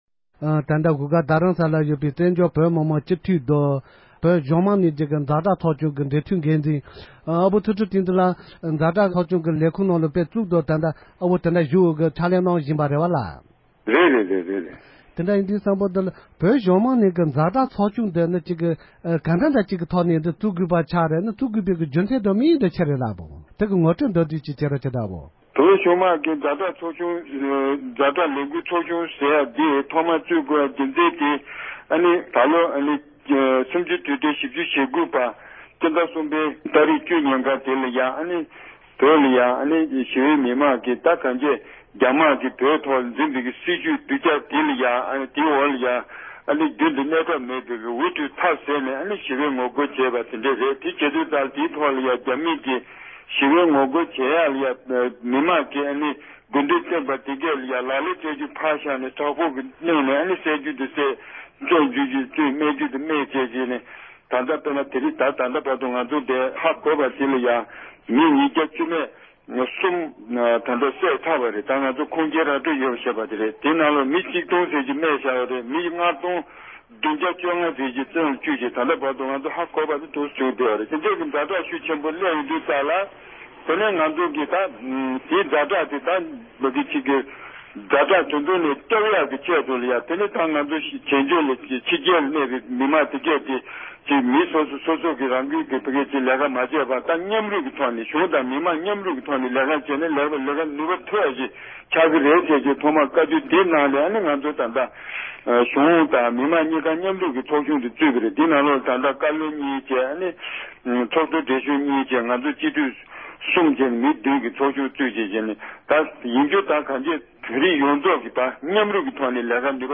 ༄༅༎ད་རིང་དེང་དུས་ཀྱི་རྒྱ་ནག་ཟེར་བའི་ལེ་ཚན་ནང་ཉེ་ཆར་གསར་བཙུགས་བྱས་པའི་བོད་གཞུང་མང་གཉིས་ཀྱི་ཛ་དྲག་ལས་དོན་ཚོགས་ཆུང་གིས་ད་བར་ལས་འགུལ་ཇི་སྤེལ་ཡོད་མེད་སྐོར་མདོ་སྟོད་སྤྱི་འཐུས་ཚུལ་ཁྲིམས་བསྟན་འཛིན་ལགས་ལ་བཅར་དྲི་ཞུས་པ་དེ་གསན་རོགས་གནང༌༎